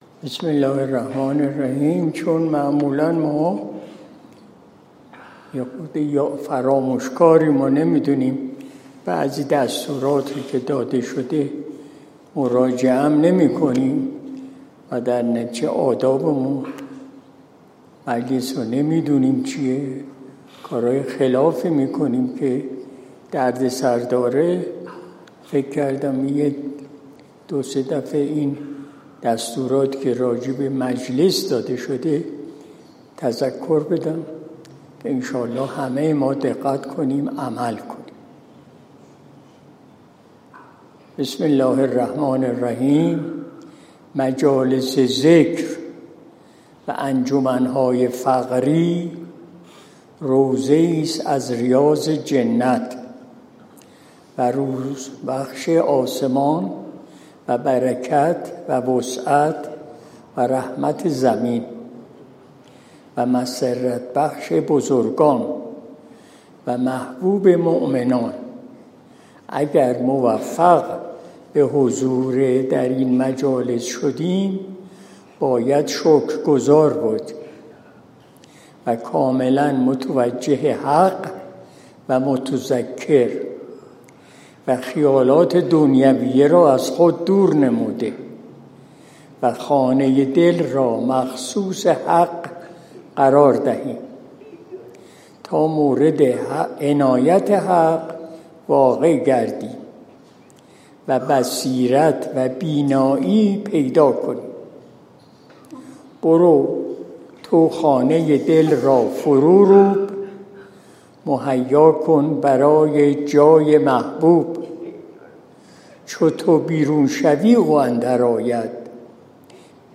مجلس شب دوشنبه ۱۲ شهریور ماه ۱۴۰۲